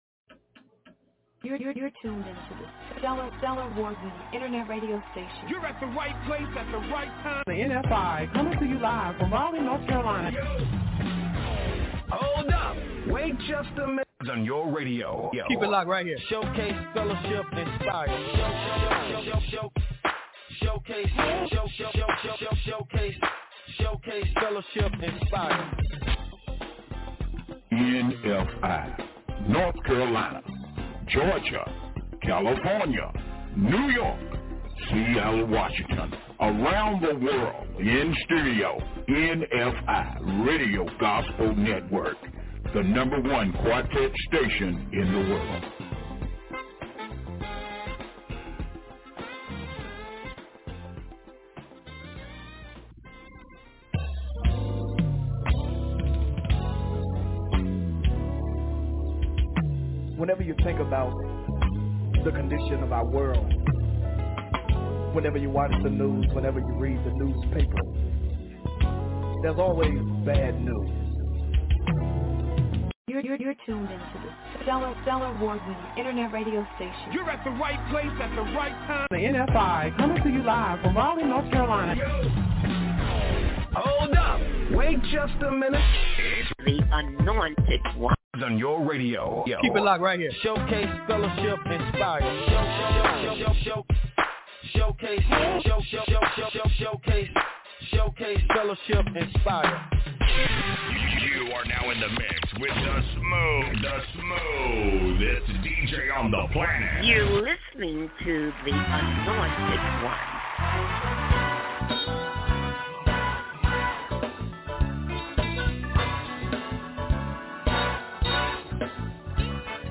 LIVE 11:00am til 2:00pm
with the very best in Quartet and Contemporary Gospel music